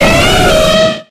DONPHAN.ogg